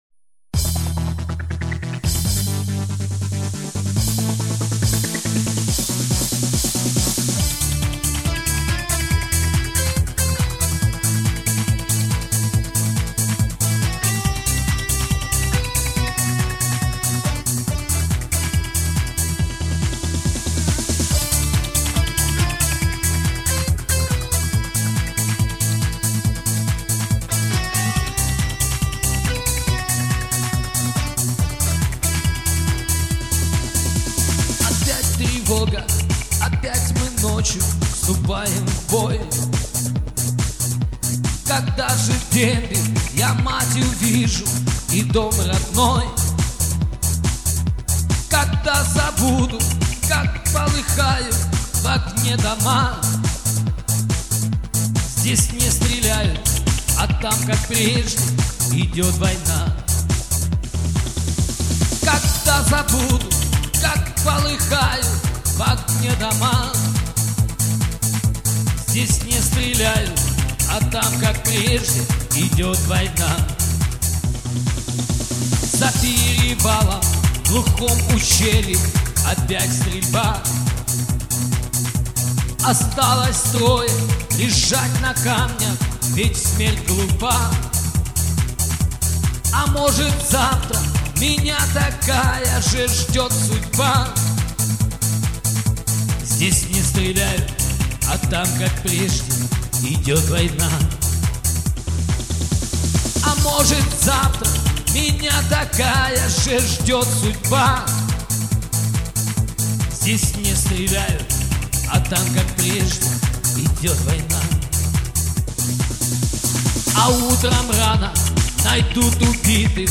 Уличные музыканты - "Афганцы" - Дембельский альбом (Название условное)